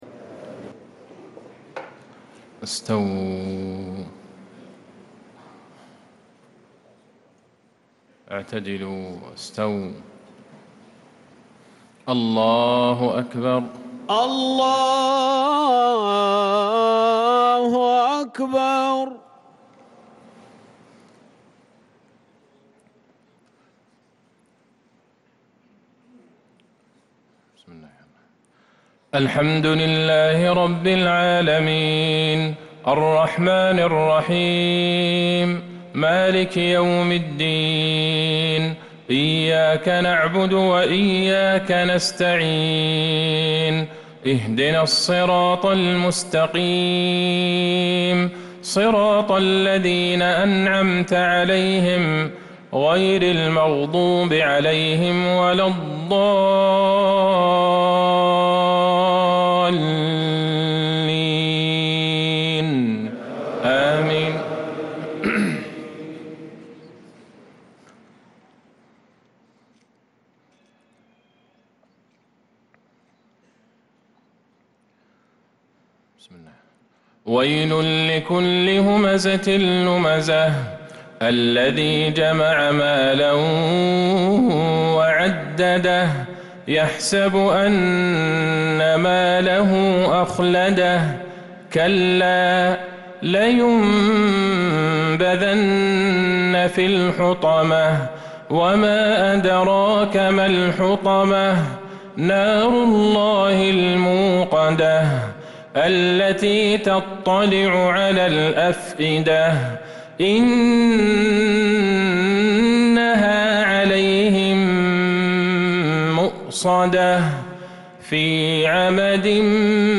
مغرب السبت ٨ صفر١٤٤٧ سورتي الهمزة والماعون كاملة | maghrib prayer surah al-humaza and al-maun 2-8-2025 > 1447 🕌 > الفروض - تلاوات الحرمين